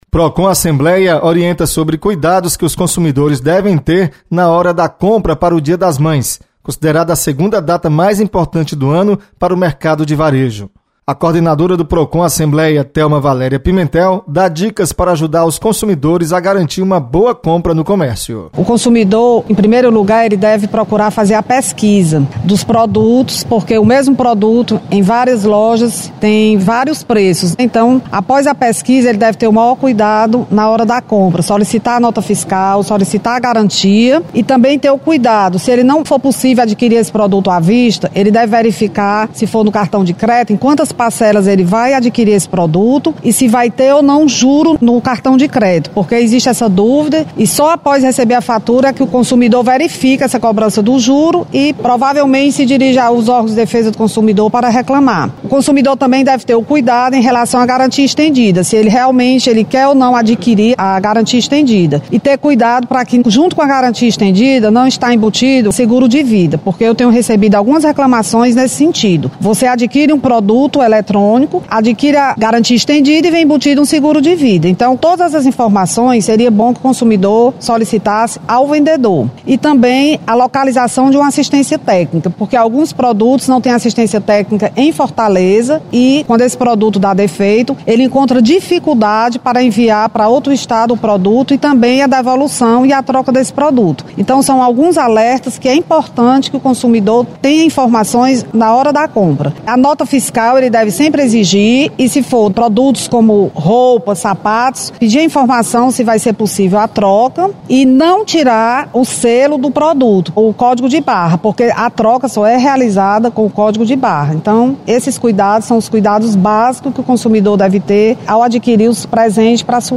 Procon Assembleia informa sobre cuidados na hora de comprar presente para o dia das mães. Repórter